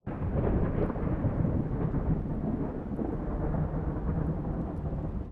sfx_闷雷声.wav